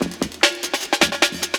61 LOOP06.wav